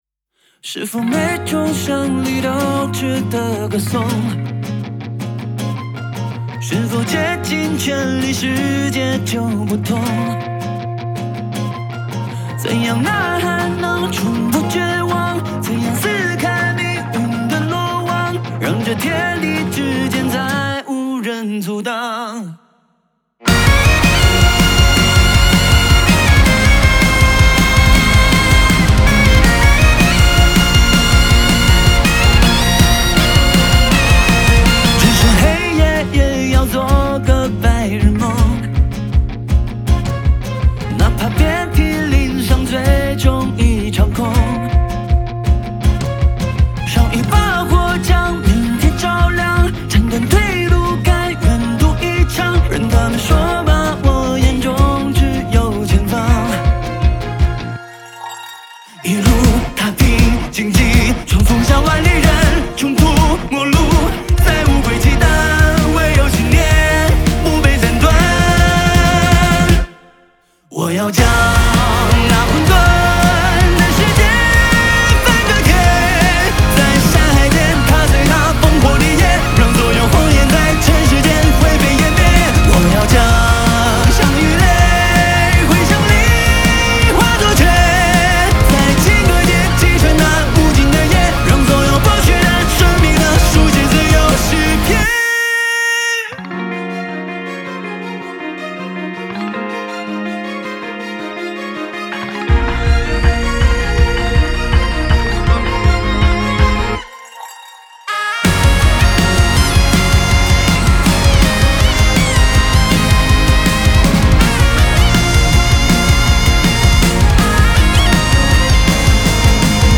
Ps：在线试听为压缩音质节选，体验无损音质请下载完整版
吉他
贝斯
唢呐
和声